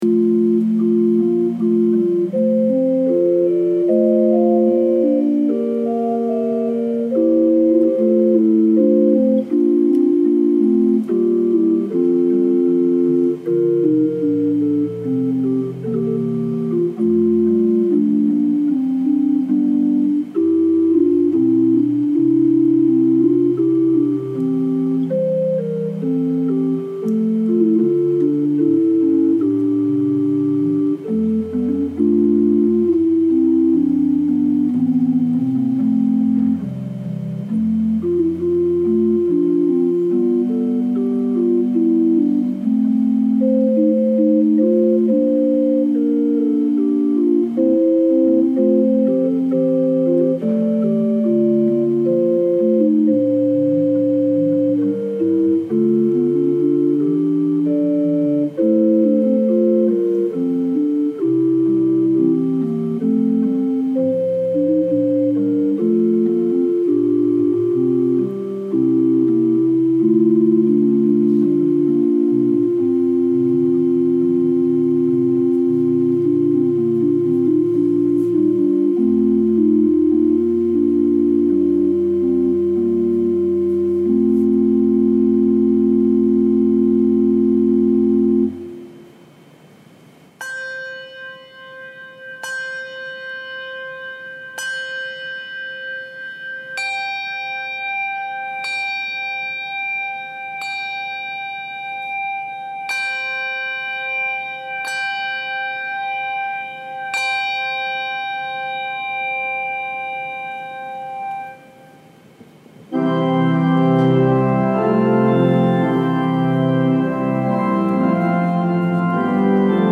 Sermon Notes
This is a modified version of our 11 a.m. Worship service as outlined in the bulletin (available for download above).
Thanks to our lead singers, ministers and support staff for teaming together.